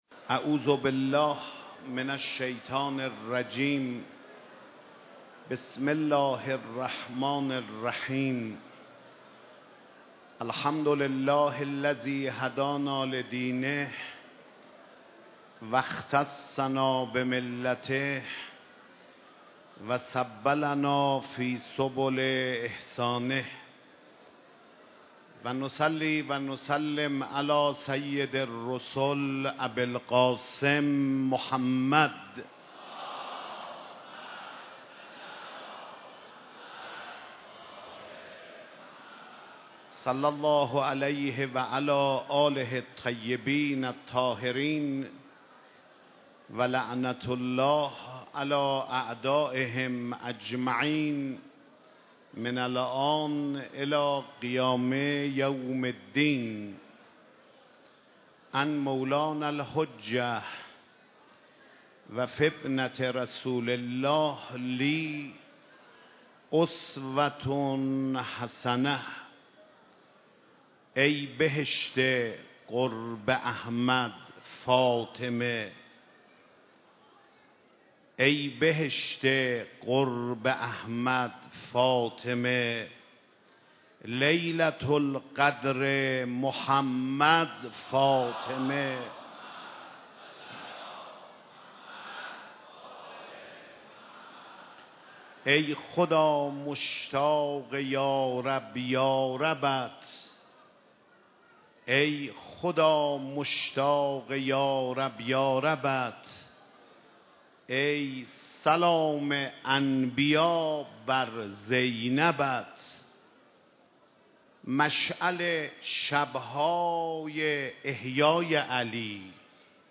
سخنرانی حجت الاسلام خاتمی
مداحی حاج محمود کریمی
شب سوم فاطمیه